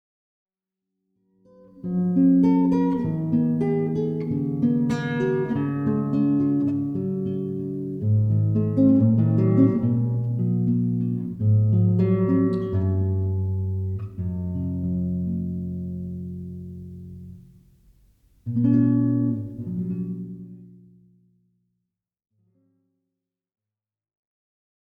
28. (01:37) Guitare (Instrumental) (+0.99 EUR)